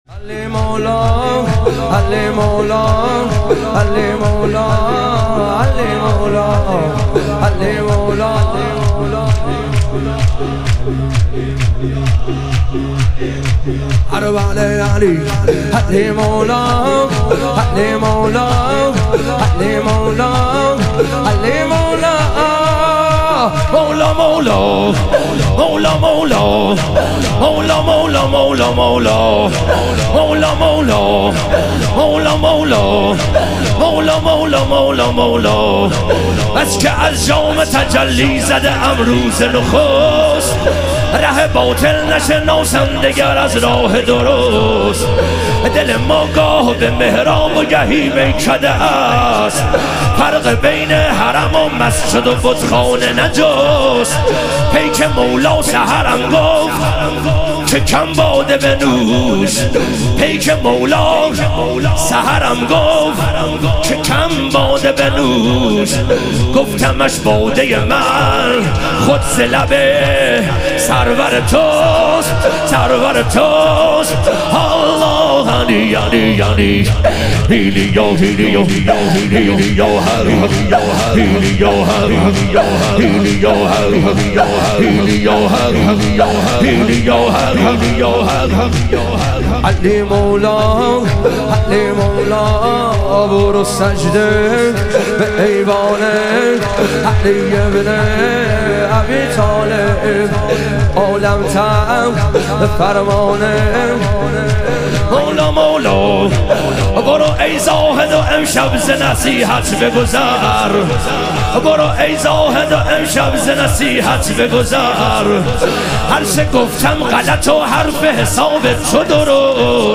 شهادت حضرت زینب کبری علیها سلام - شور